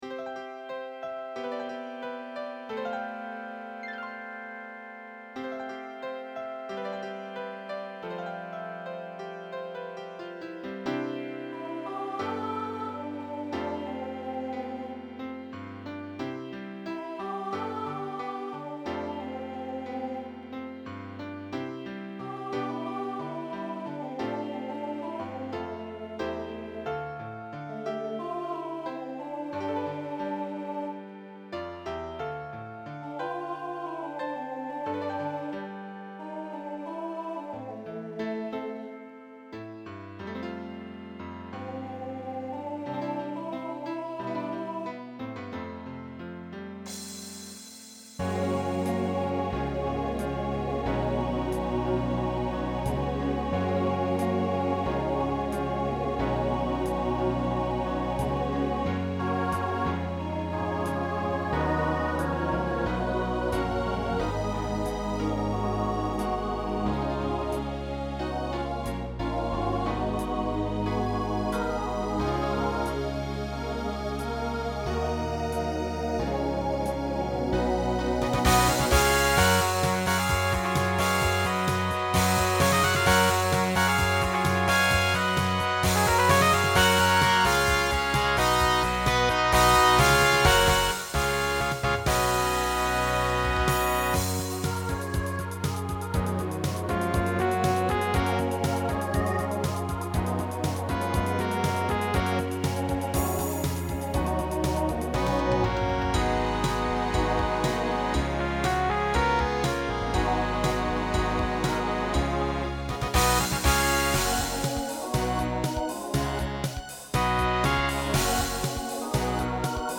Voicing SATB Instrumental combo Genre Broadway/Film , Rock